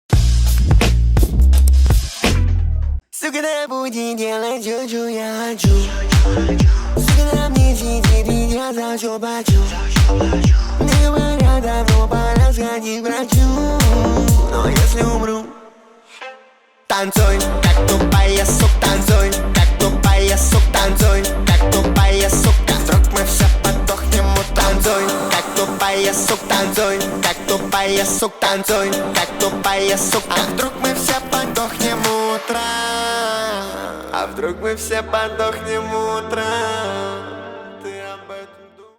• Качество: 320, Stereo
Mashup